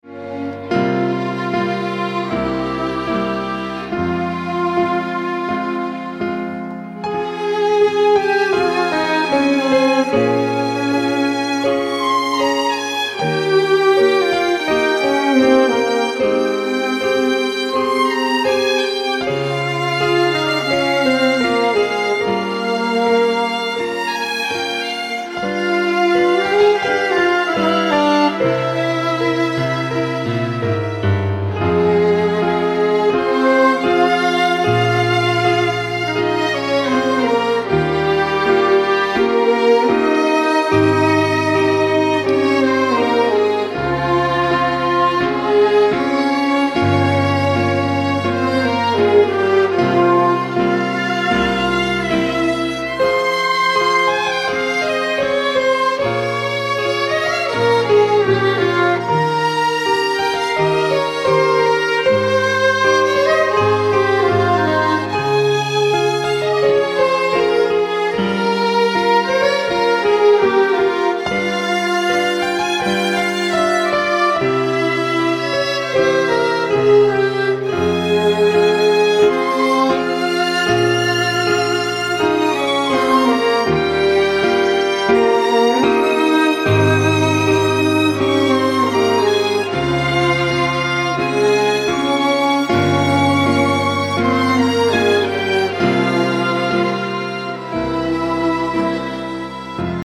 Запись скрипки